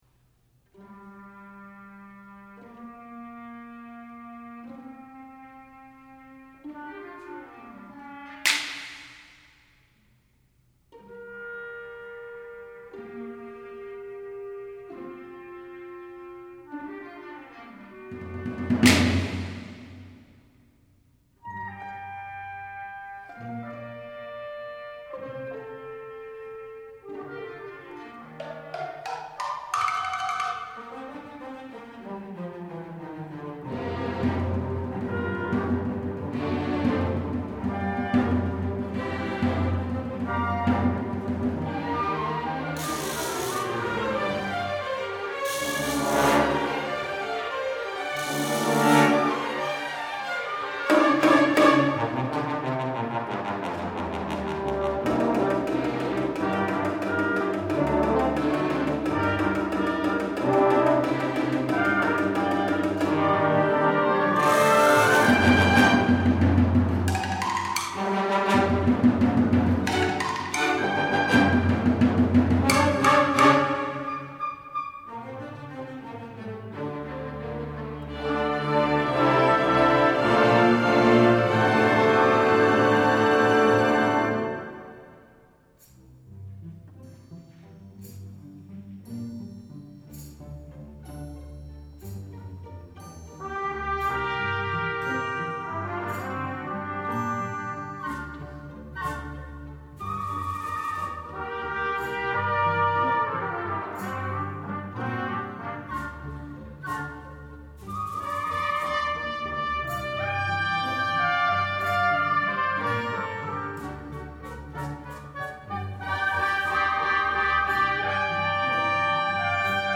for Orchestra (1994)
And the percussion section is kept especially busy.